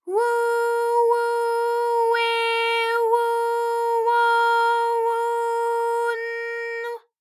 ALYS-DB-001-JPN - First Japanese UTAU vocal library of ALYS.
wu_wu_we_wu_wo_wu_n_w.wav